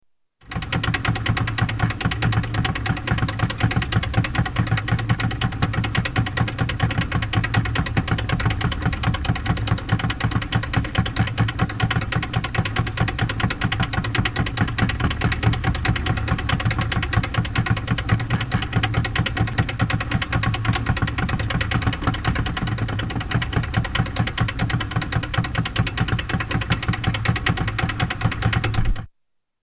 Escalator
Tags: Travel Sounds of Austria Austria Holidays Vienna